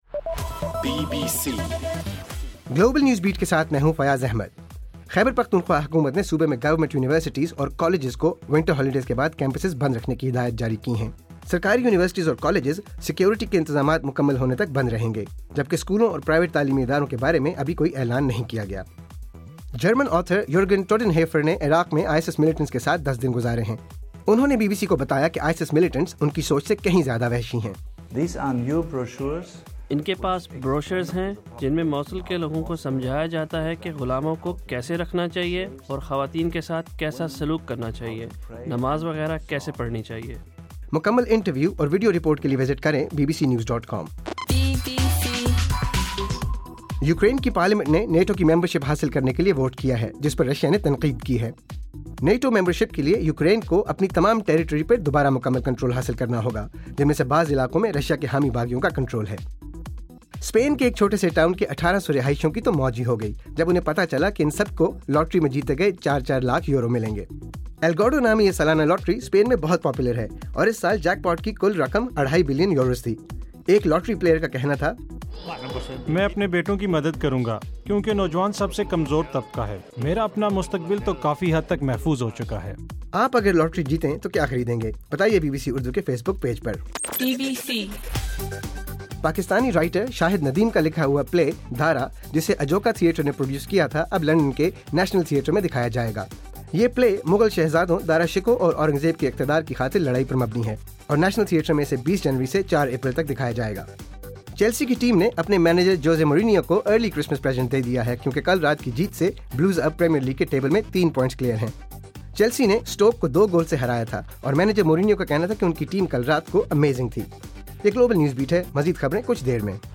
دسمبر 23: رات 8 بجے کا گلوبل نیوز بیٹ بُلیٹن